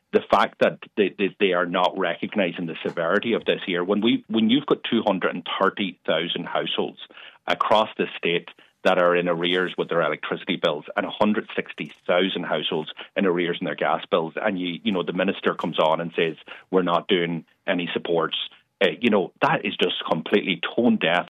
Sinn Fein’s Finance spokesperson Pearse Doherty says his party would change the way the market operates: